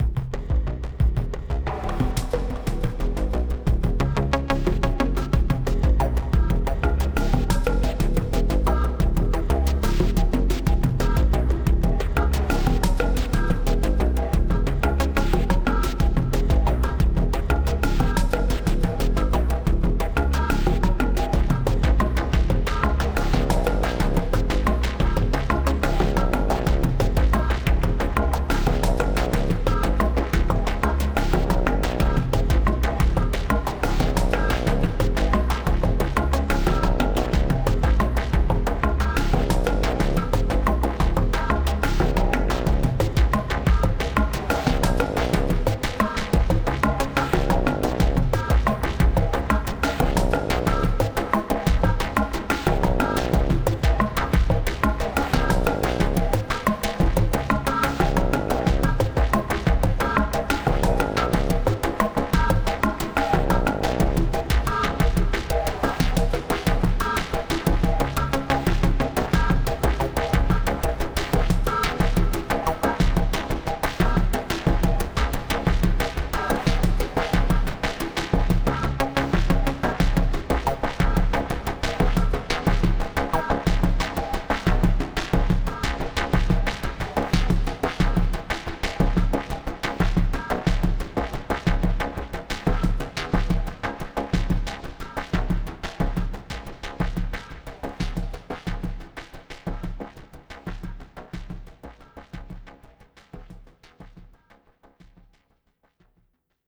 Strange track in post apocalyptic style.